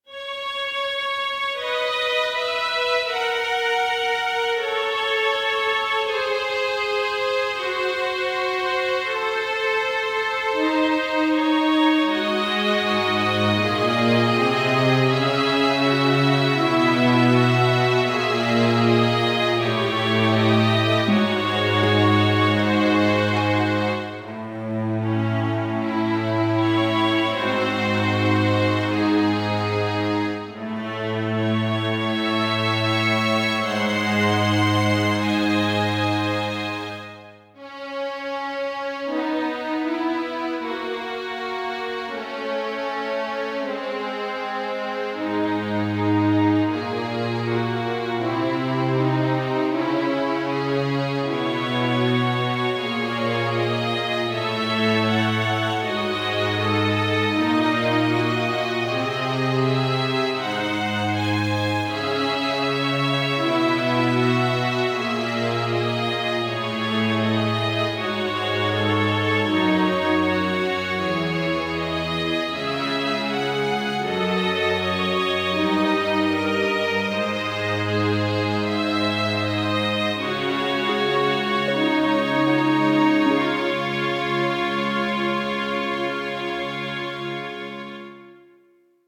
-oggをループ化-   悲しい 弦楽 1:30 mp3